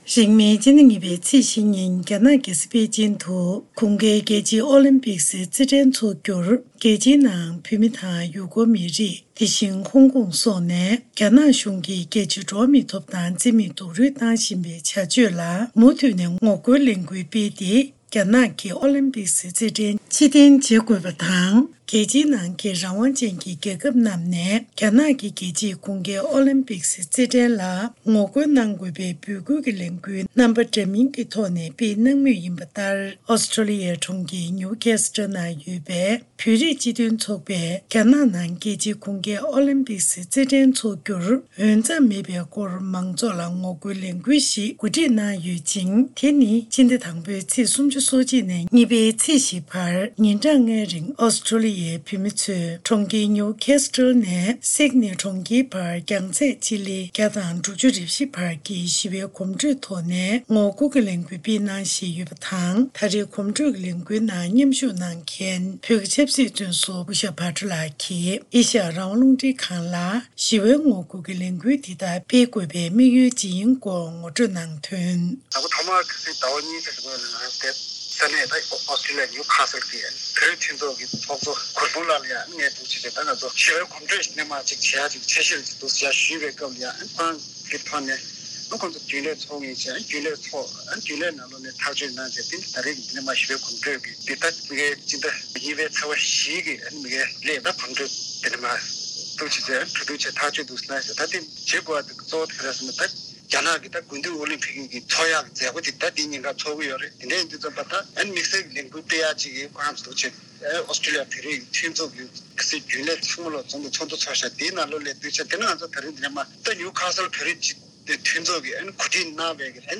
གནས་ཚུལ་ཕྱོགས་སྒྲིག་ཞུས་པ་ཞིག